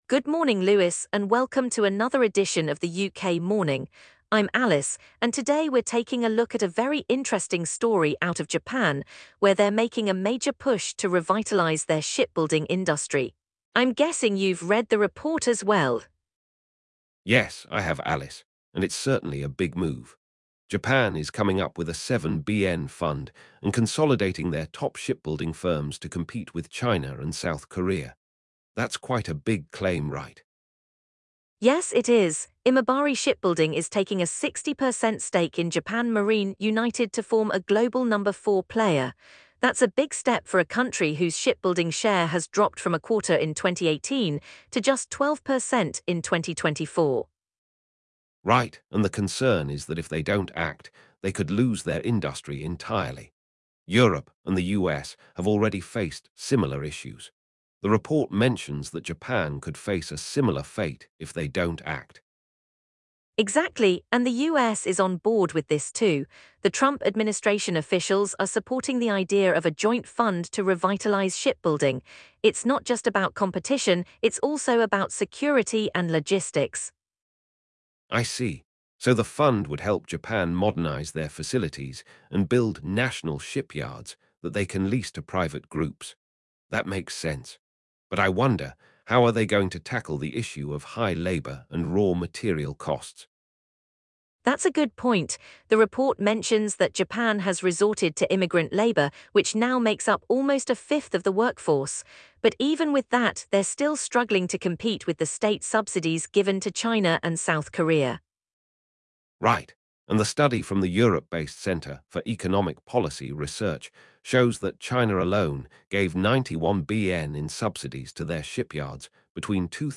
UK morning business news